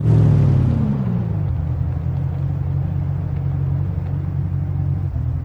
slowdown_slow.wav